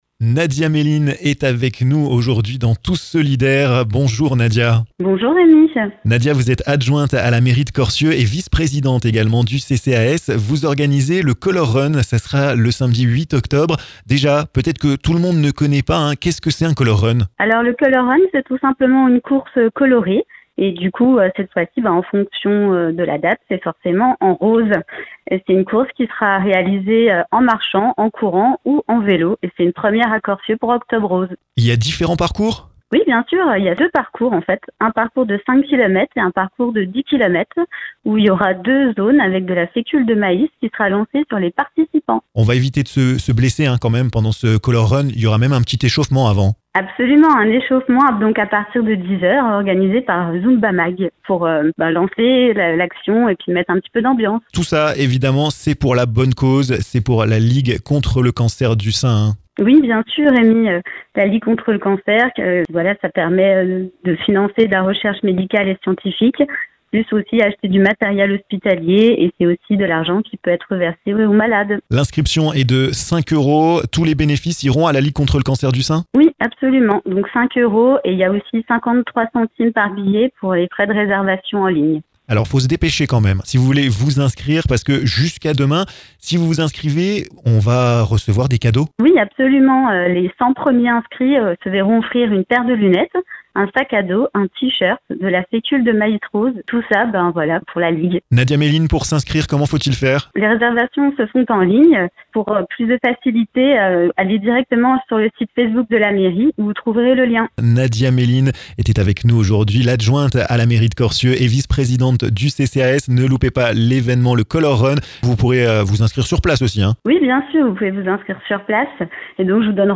Octobre Rose se prépare à Corcieux. Nadia Meline, adjointe à la mairie de Corcieux et vice présidente du CCAS, vous explique le déroulement de cette journée!